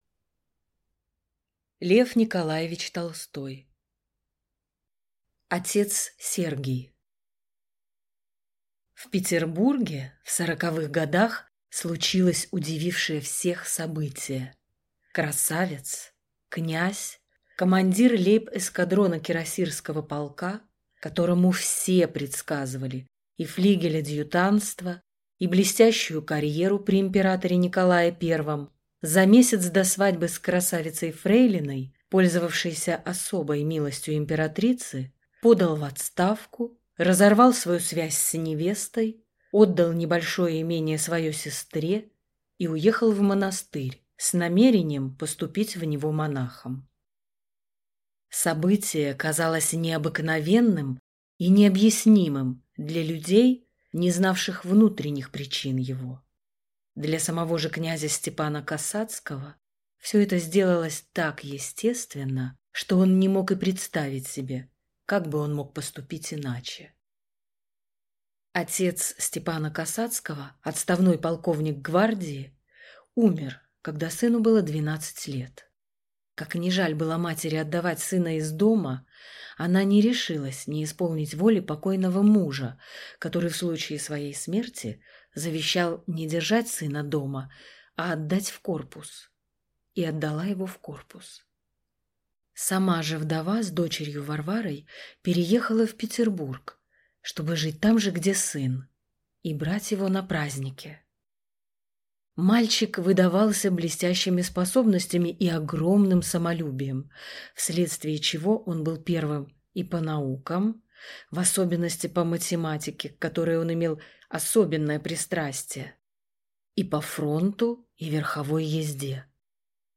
Аудиокнига Отец Сергий | Библиотека аудиокниг